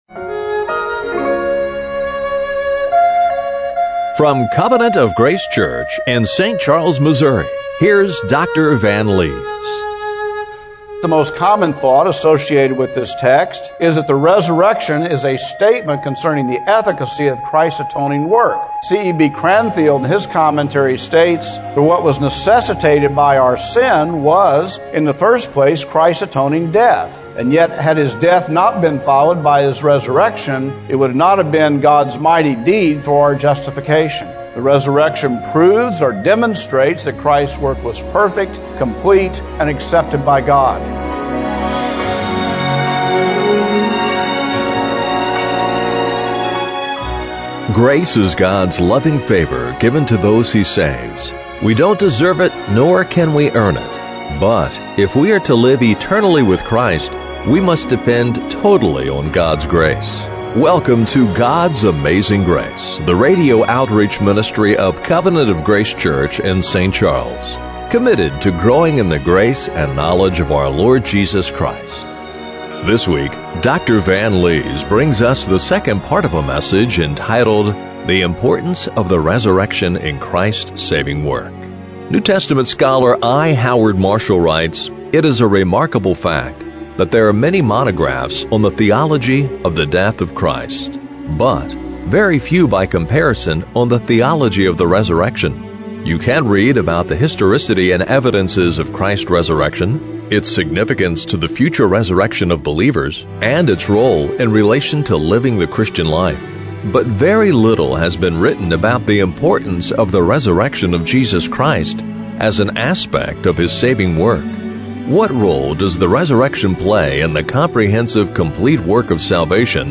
Service Type: Radio Broadcast